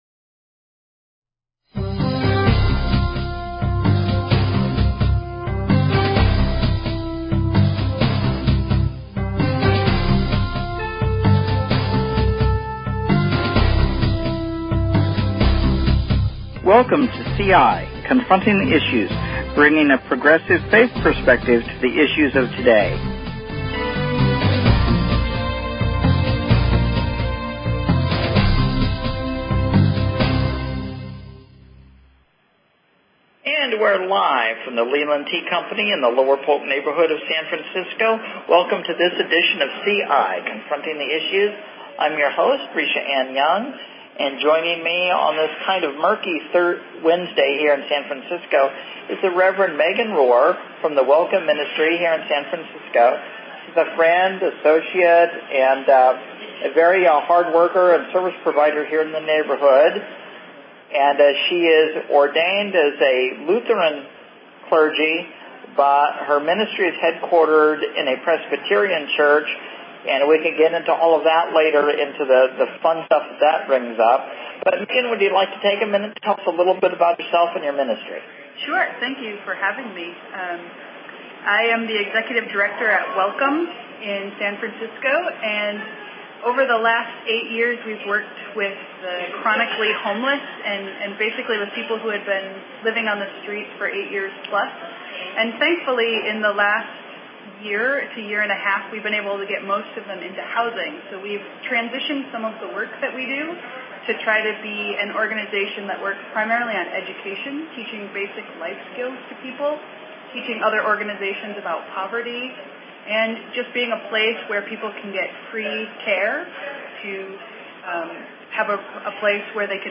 Talk Show Episode, Audio Podcast, CI_Confronting_the_Issues and Courtesy of BBS Radio on , show guests , about , categorized as
Live from the Leland Tea Company, in San Francisco. Bringing a progressive faith perspective to the issues of the day.